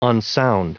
Prononciation du mot unsound en anglais (fichier audio)
Prononciation du mot : unsound